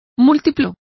Complete with pronunciation of the translation of multiples.